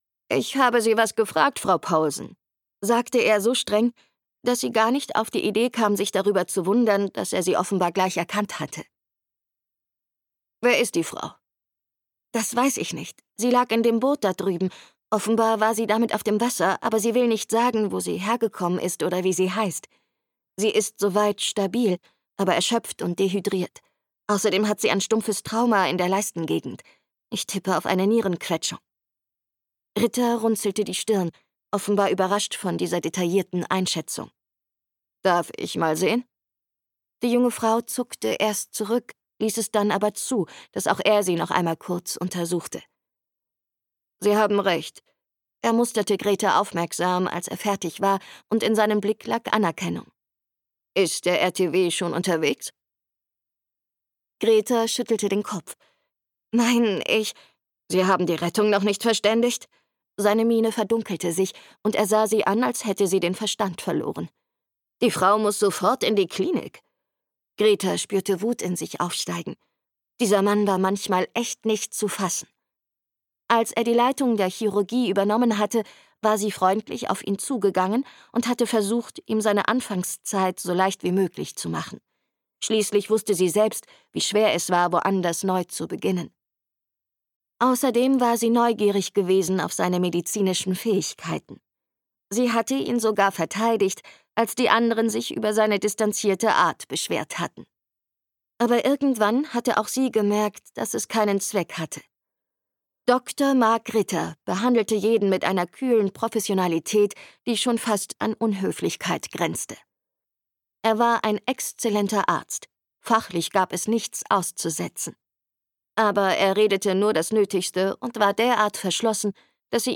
Das Inselkrankenhaus: Sommerstürme - Liv Helland | argon hörbuch
Gekürzt Autorisierte, d.h. von Autor:innen und / oder Verlagen freigegebene, bearbeitete Fassung.